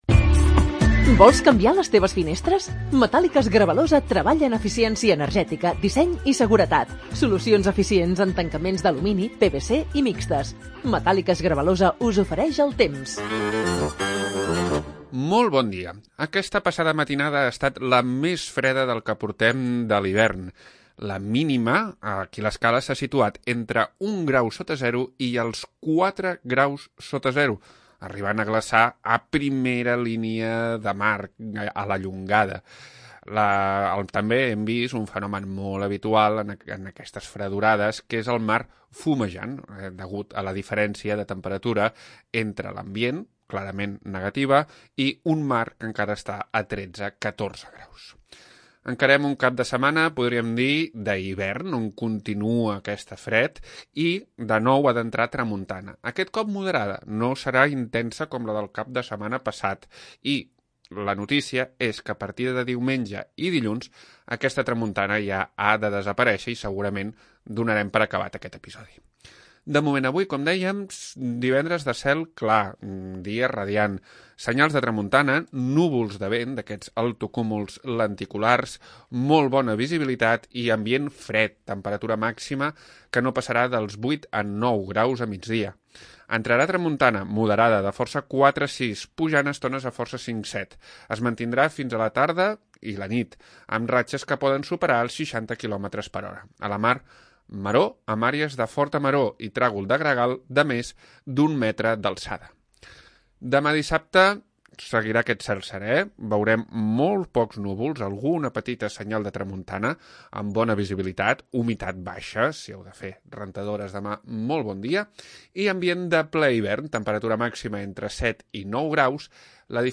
Previsió meteorològica 27 de Gener de 2023